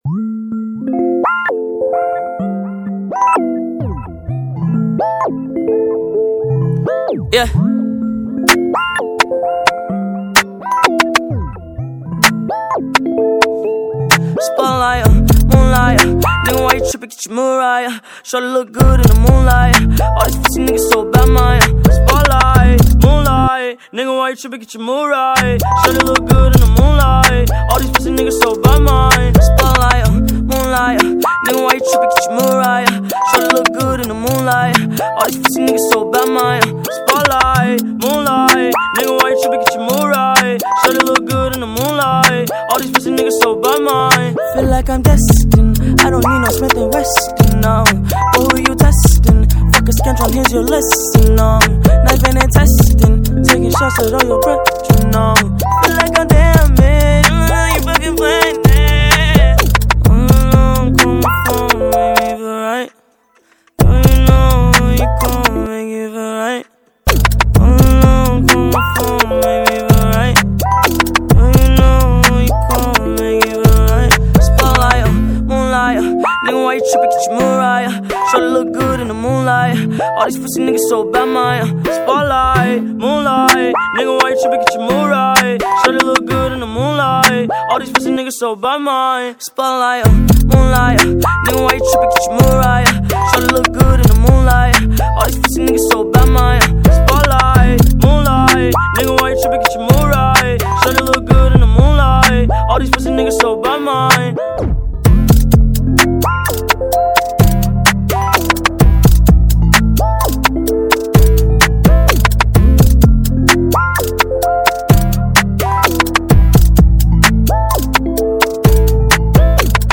2024-12-20 23:53:56 Gênero: Phonk Views